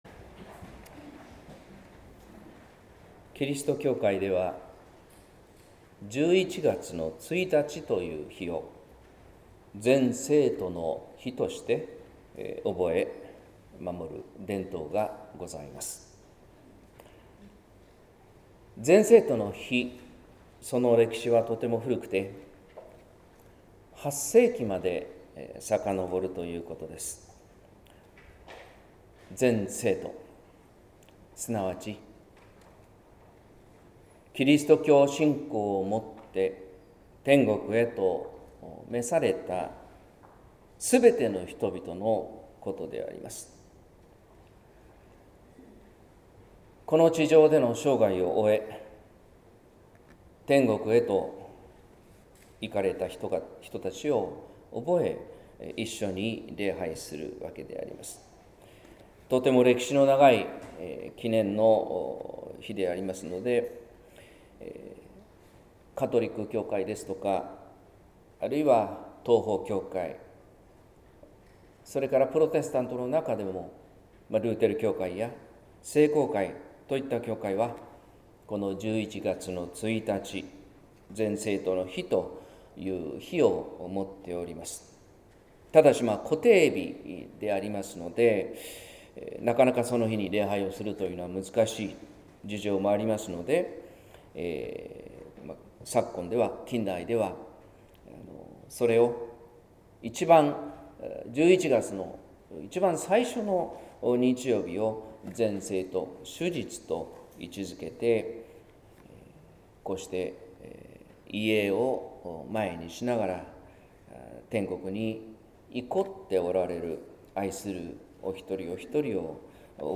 説教「天国はだれのもの」（音声版） | 日本福音ルーテル市ヶ谷教会